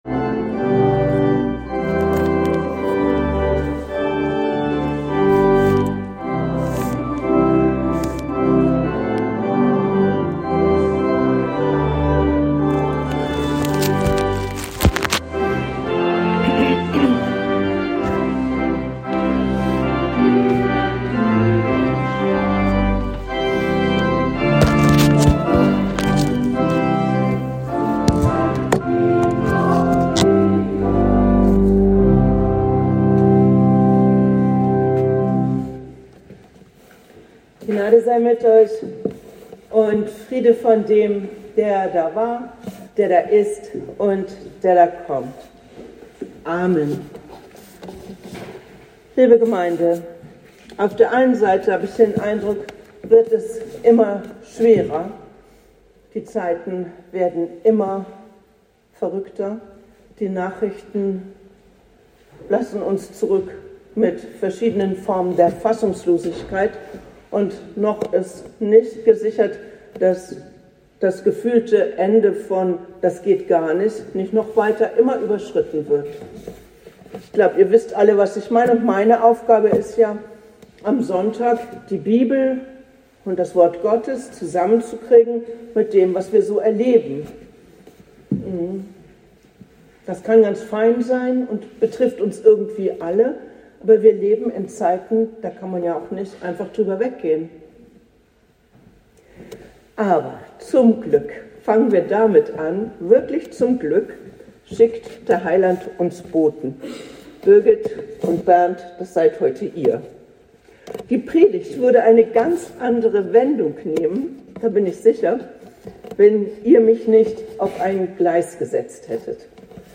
Gottesdienst am 02. März 2025